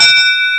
bell1.wav